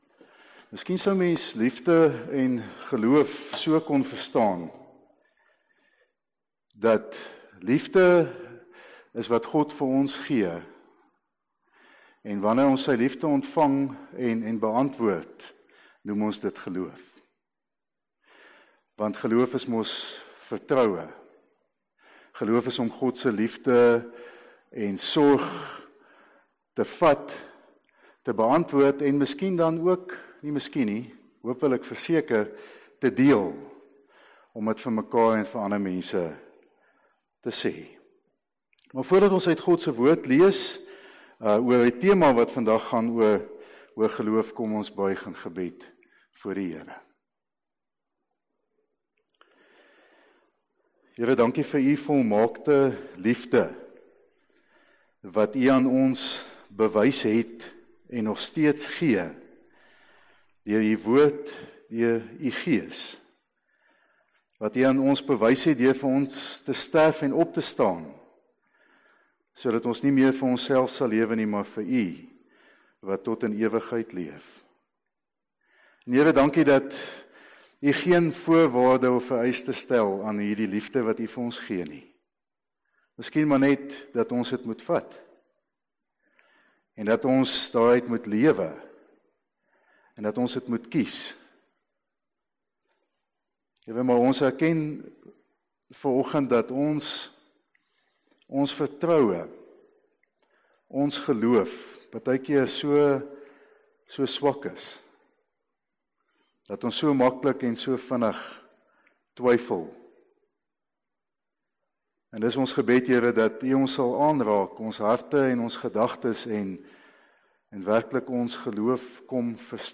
Erediens - 18 Oktober 2020